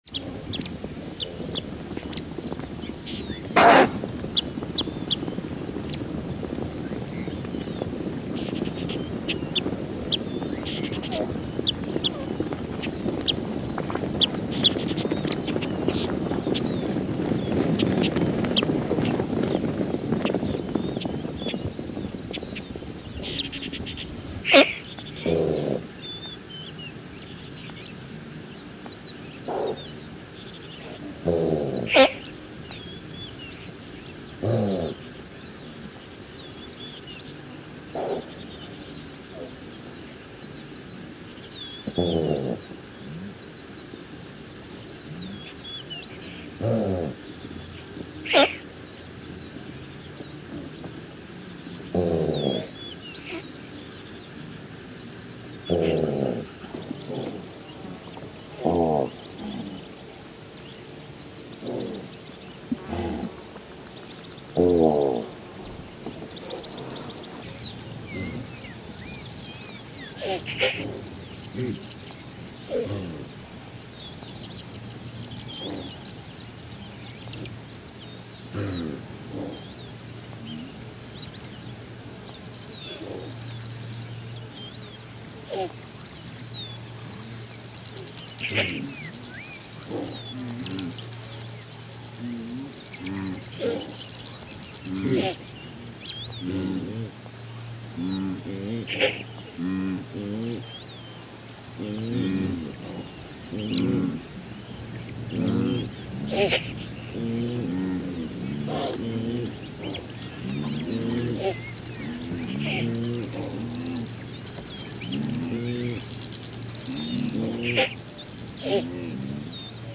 Звуки газели
Звук газелей Томпсона: сначала слышно бегущее стадо